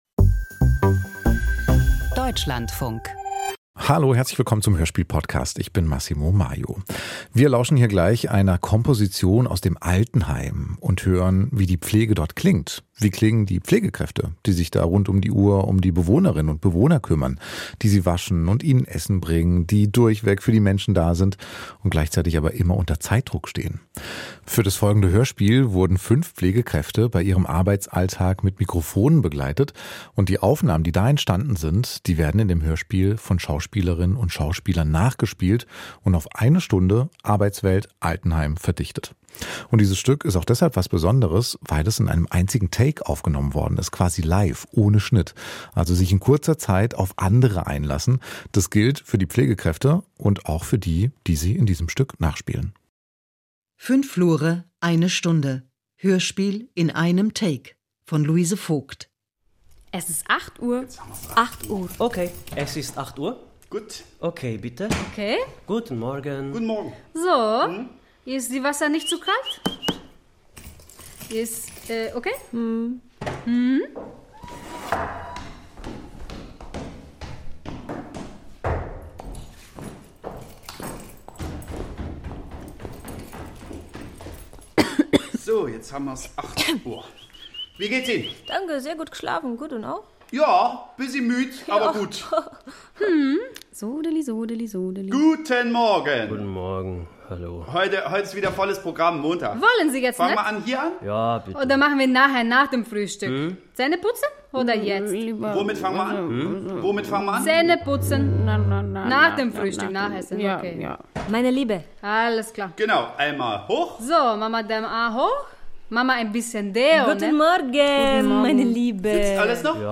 Hörspiel: Wahrnehmungen im Lebensraum Altenheim - Fünf Flure, eine Stunde
• O-Ton-Experiment • Eine Stunde lang begleiten die Mikrofone fünf Pflegekräfte im Altenheim. Pflegende und Gepflegte stehen unter andersartigem Zeitdruck: Die einen müssen effizient sein, die anderen befinden sich in ihrer letzten Lebensphase.